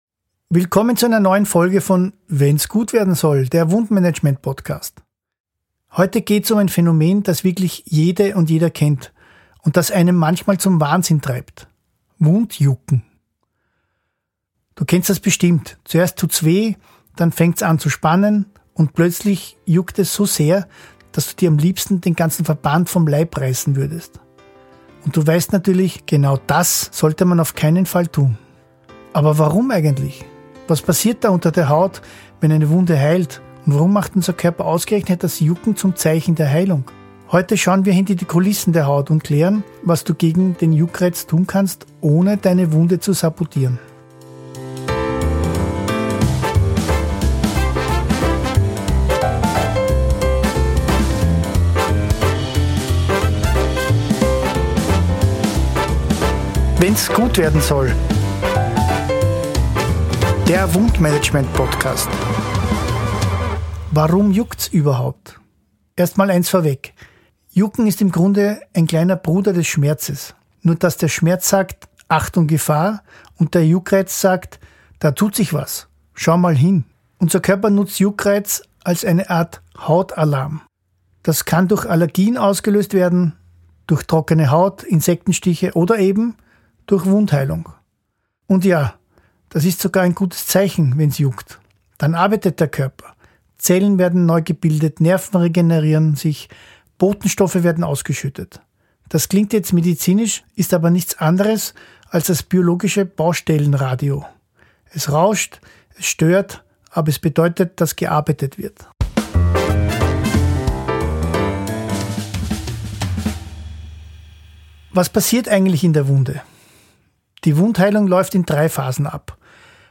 Bearbeitung & Sounddesign: